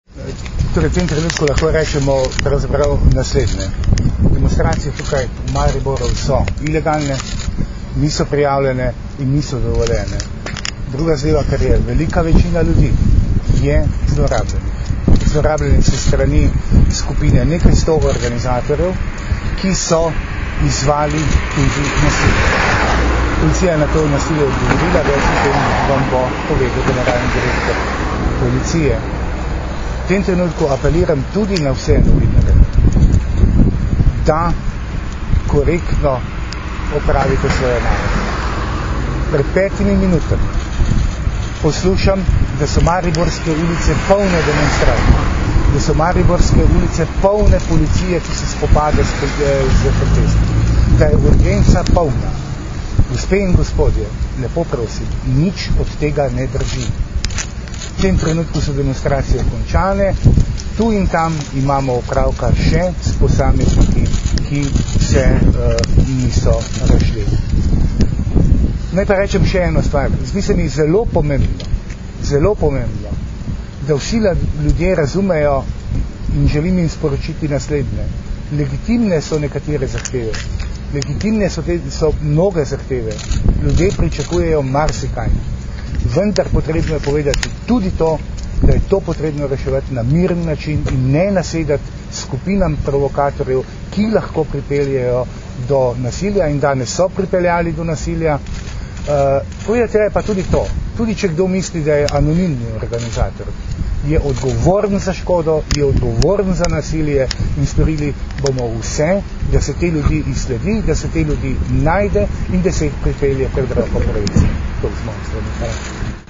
Zvočni posnetek izjave notranjega ministra (mp3)
VinkoGorenak.mp3